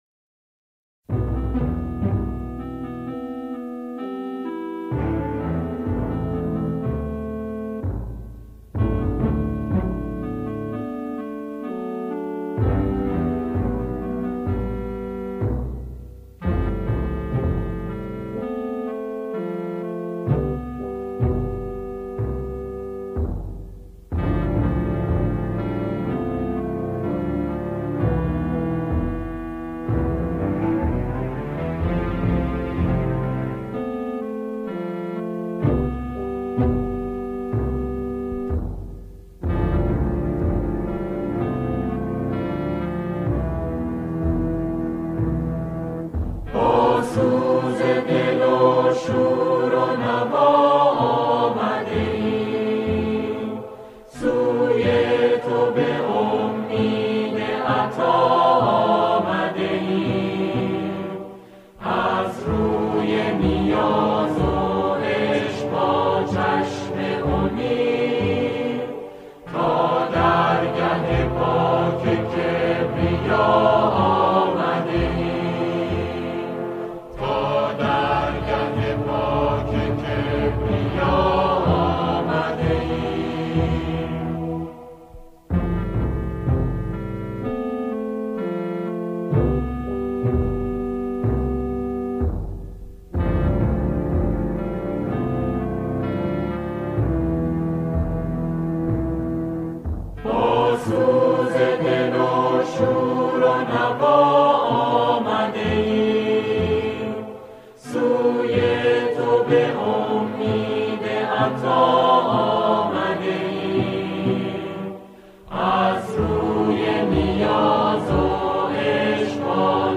آنها در این قطعه، شعری را درباره ماه رمضان همخوانی می‌کنند.